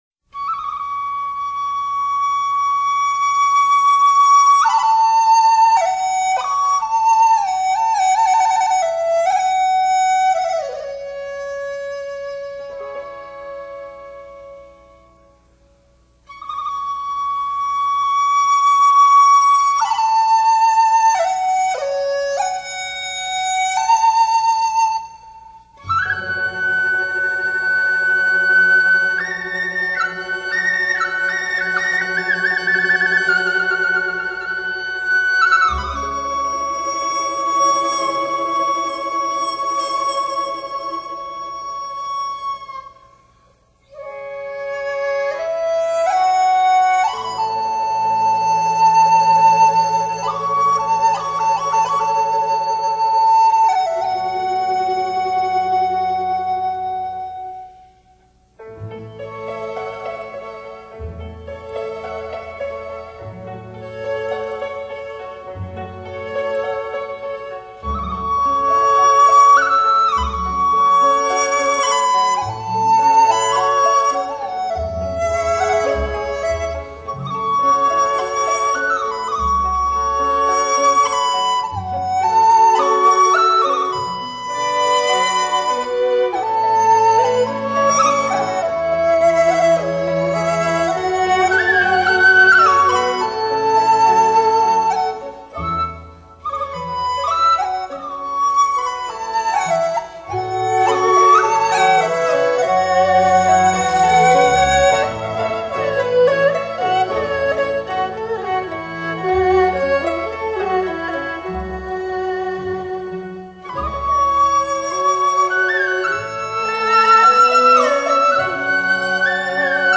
（笛子独奏）